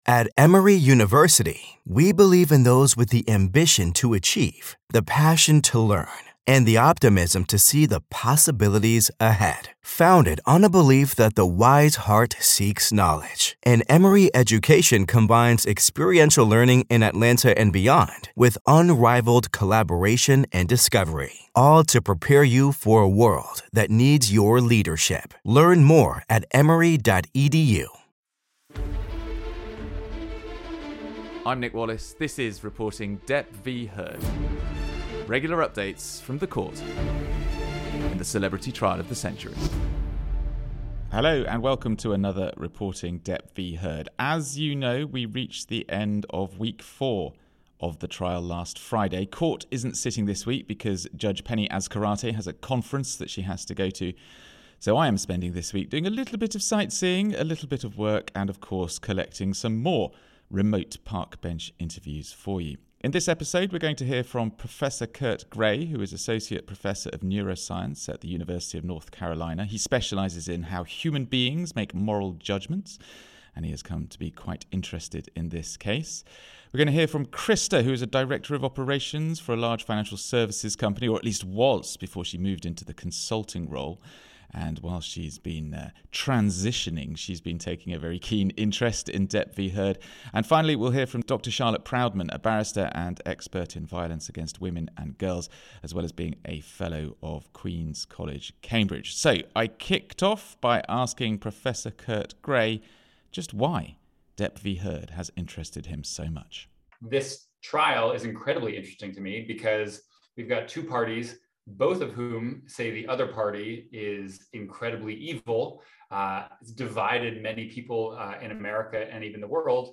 lots of remote park bench interviews (along with some sightseeing).